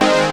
SYNTH GENERAL-2 0006.wav